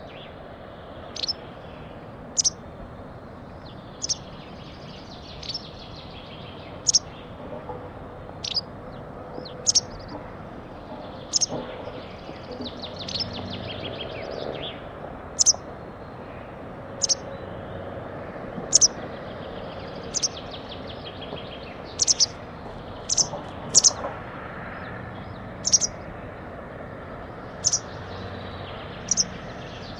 bergeronnette-grise.mp3